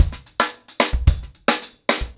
TimeToRun-110BPM.33.wav